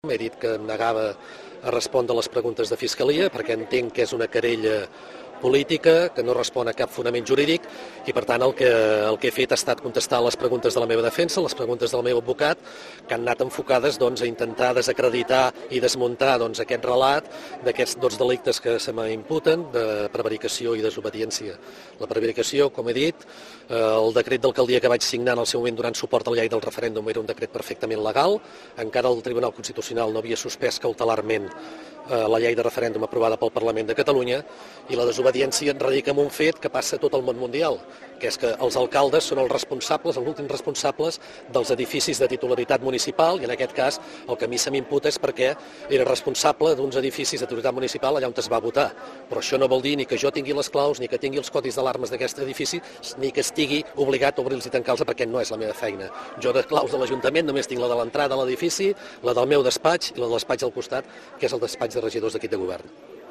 Posteriorment i en declaracions als mitjans presents Lluís Sais ha dit això